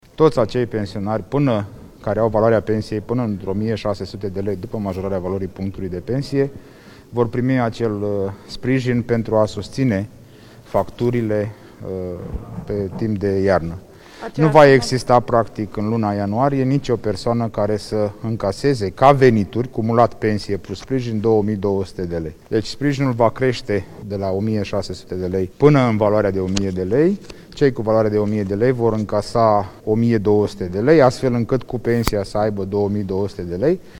Ministrul Muncii, Marius Budăi, a anunțat la finalul ședinței coaliției că niciun bătrân cu pensia de până în 1.600 de lei nu va primi în ianuarie mai puțin de 2.200 de lei.
14dec-08-budai-despre-ajutor-diferentiat-pensii.mp3